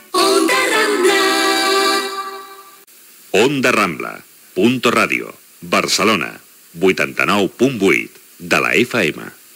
Indicatiu de la ràdio i de la cadena